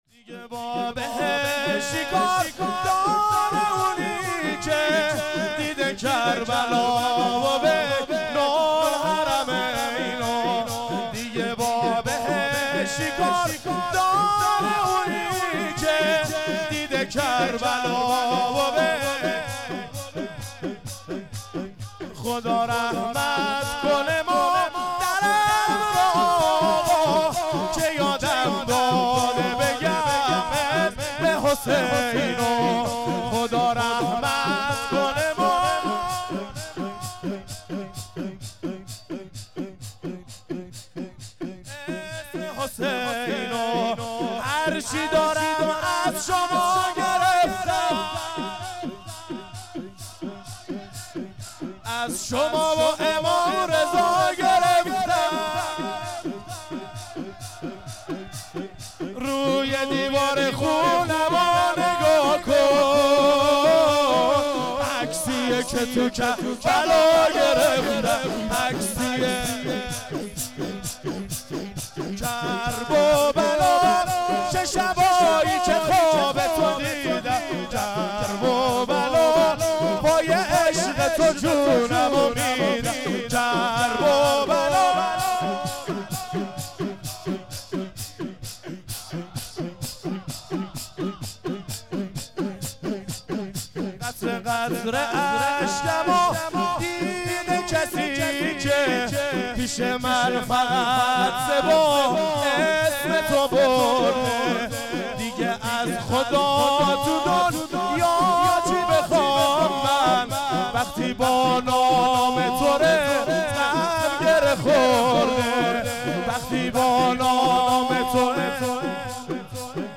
شور،دیگه با بهشت چیکار داره